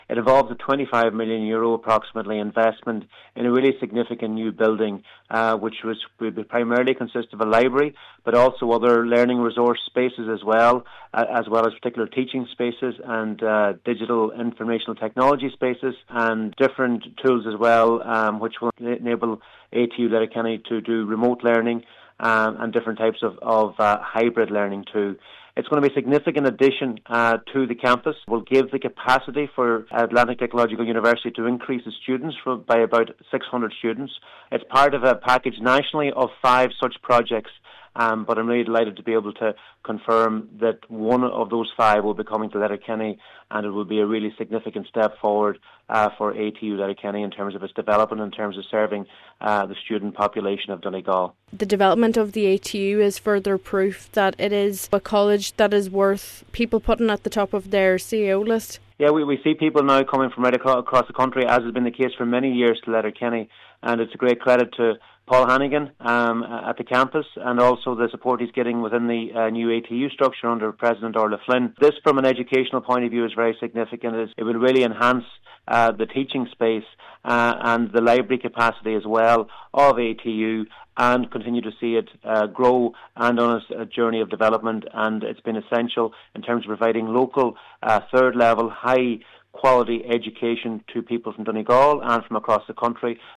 Welcoming the development, Donegal Minister of State, Charlie McConalogue says the announcement shows a commitment to strengthening the county’s education facilities: